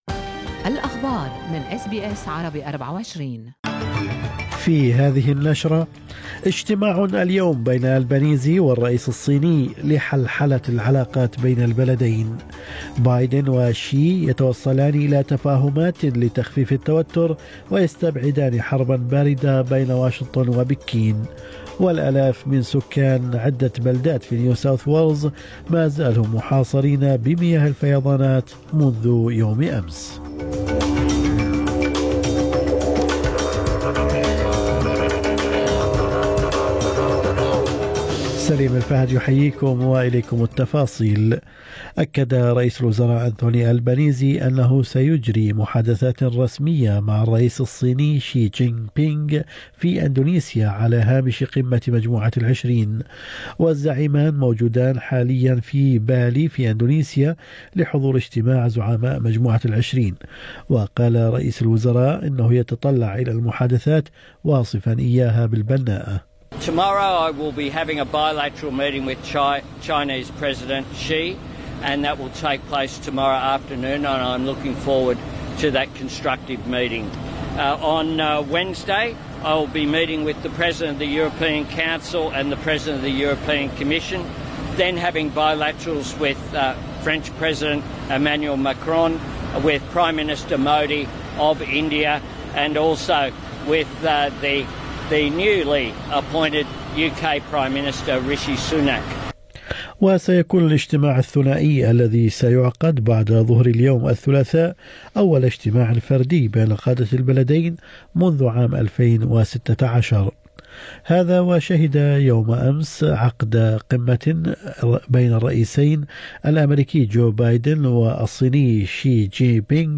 نشرة أخبار الصباح 15/11/2022